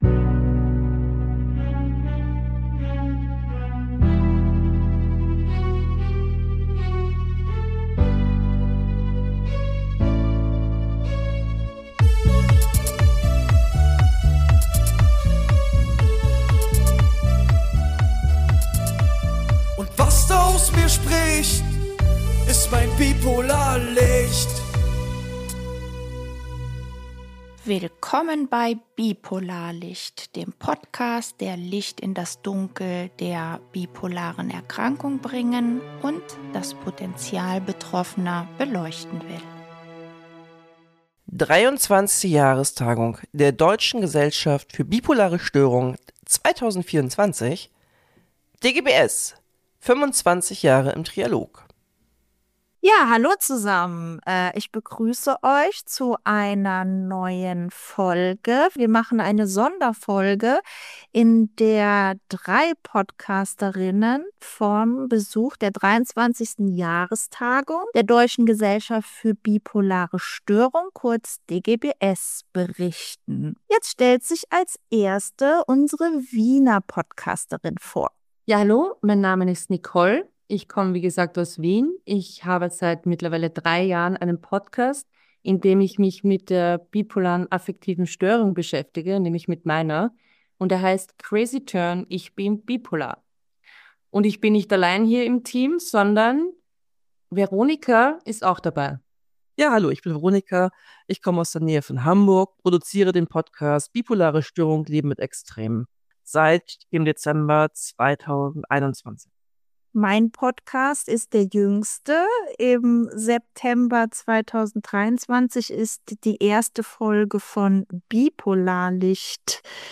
Freut euch auf zwei Folgen voller Inspiration, Fachwissen und lebendiger Stimmen direkt von der Tagung – hört unbedingt rein!